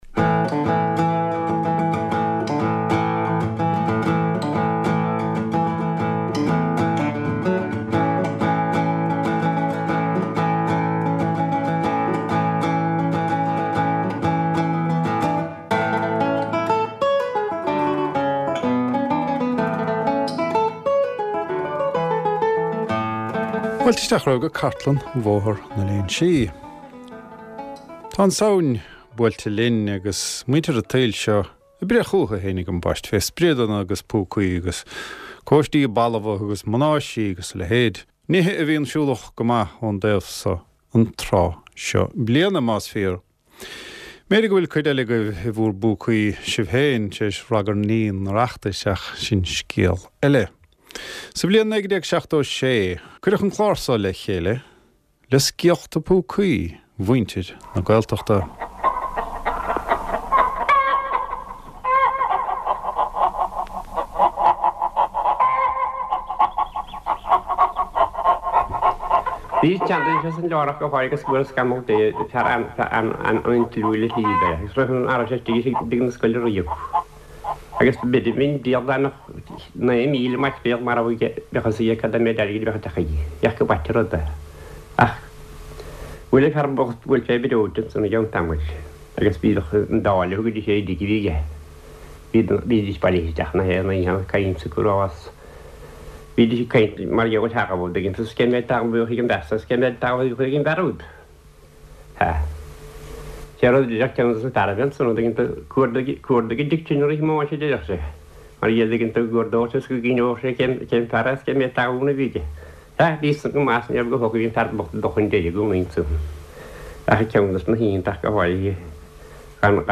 Glórtha ó chartlann Bhóthar na Léinsí - scéalta agus seanchas, amhráin agus ceol, stair agus sochaí na Gaeltachta. / Voices from RTÉ Raidió na Gaeltachta's Baile na nGall archive, including stories and folklore, songs and music, history and descriptions of the Gaeltacht community.